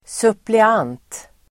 Uttal: [suple'an:t (el. -'ang:)]